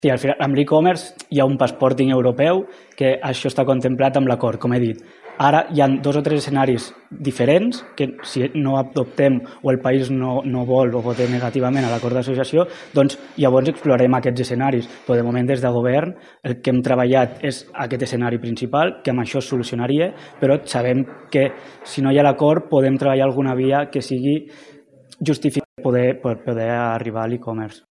En declaracions als mitjans Saura ha explicat que l’acord contempla mecanismes per harmonitzar la normativa andorrana amb la del mercat interior europeu.